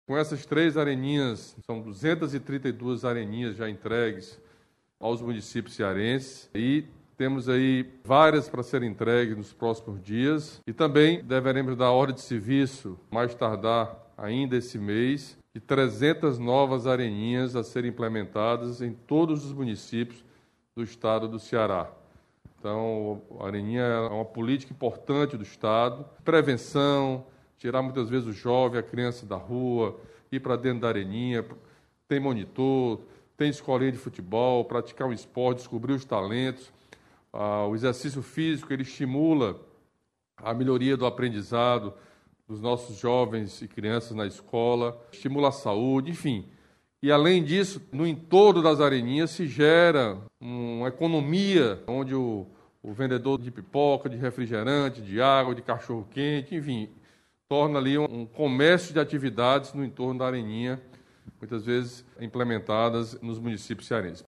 O governador Camilo Santana ressaltou que a construção de um equipamento desses vai além da questão esportiva.